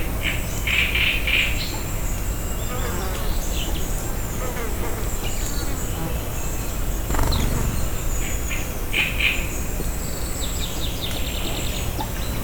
felrebbenomadar_tiszababolna00.12.wav